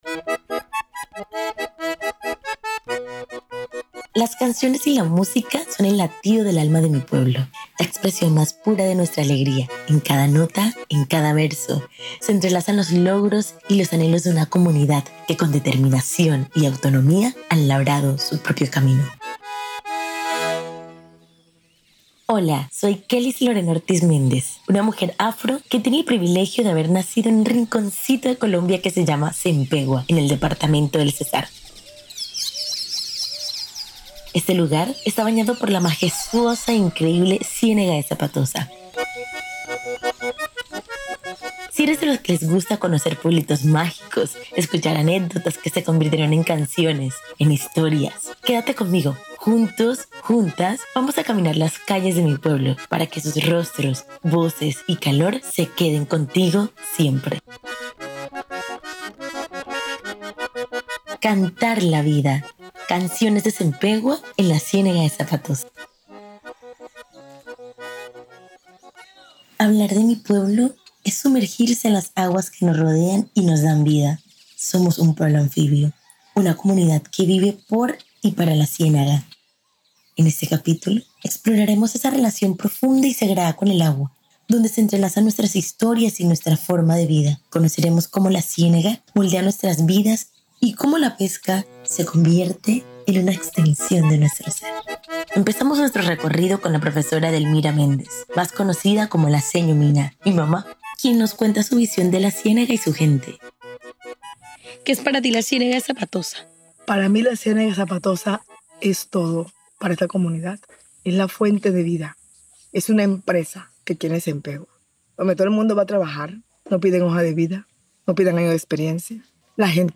En Sempegua, la Ciénaga de Zapatosa es más que un recurso: es identidad y sustento. Pescadores, madres y ancianos revelan cómo el agua define su vida, pero también los retos de depender de un solo recurso en tiempos de cambio y escasez.